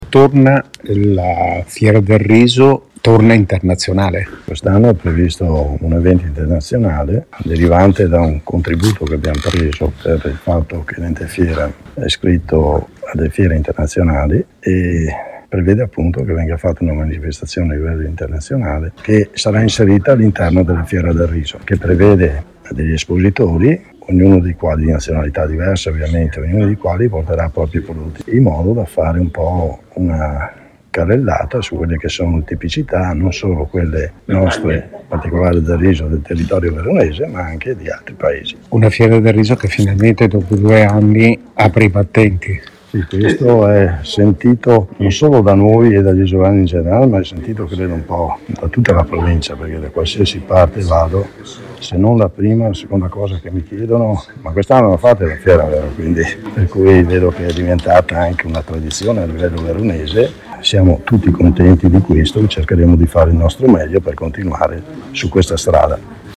Grande novità è l’INTERNAZIONALIZZAZIONE della FIERA con TASTE OF EARTH, un percorso iniziato due anni fa con la Regione Veneto che si è da poco concretizzato e di cui ci ha parlato Luigi Mirandola, sindaco di isola della Scala:
Luigi-MIrandola-Sindaco-di-Isola-della-Scala.mp3